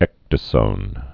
(ĕkdə-sōn)